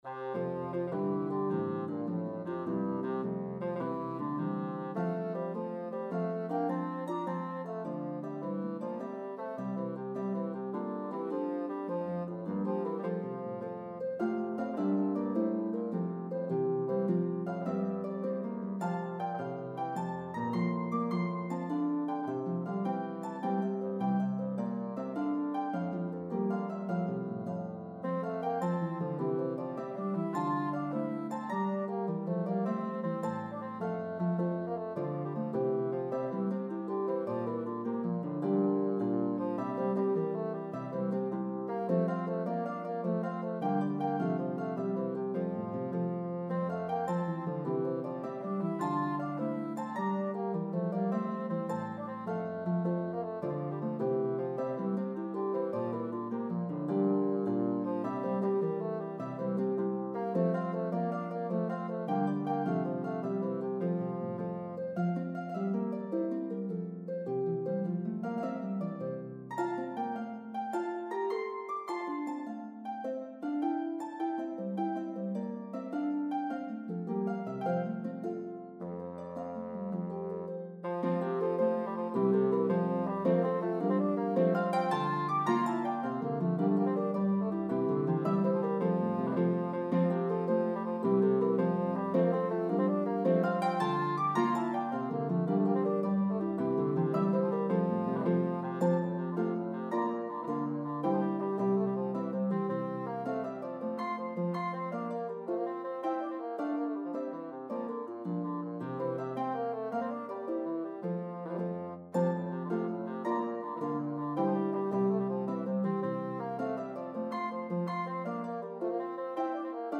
The medley progresses through 3 keys.